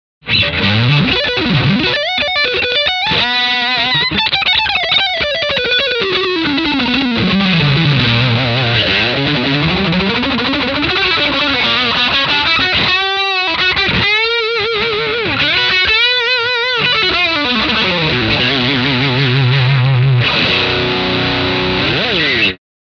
Tone Designed Distortion - Brit Heavy Distortion / American Crunch Drive
This effect pedal has two different kinds of amp simulator functions - its sonic palette ranges from the blues to British rock. From the gain control, you will get an authentic low-gain natural tube sound, a traditional rock sound(classic) and a classic American blues crunch sound (crunch).
Humbucker Pickup, Crunch Mode
Humbucker Pickup, Classic Mode
Single Pickup, Crunch Mode
Single Pickup, Classic Mode